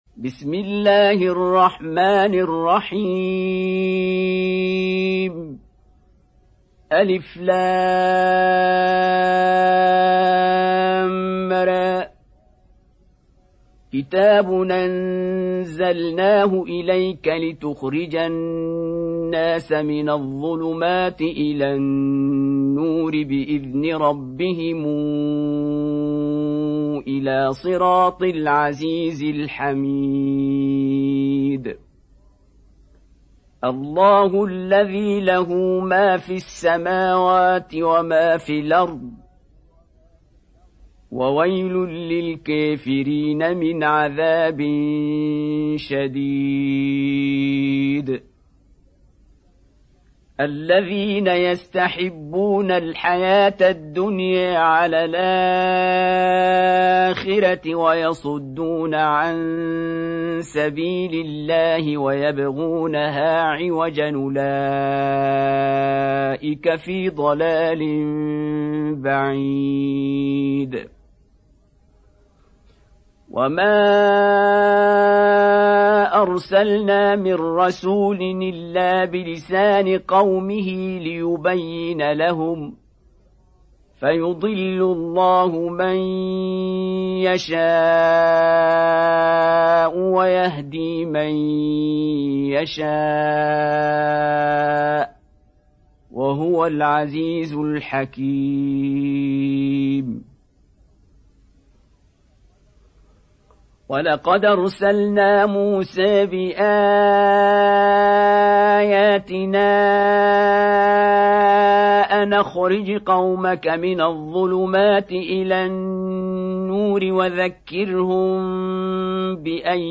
Surah Ibrahim Beautiful Recitation MP3 Download By Qari Abdul Basit in best audio quality.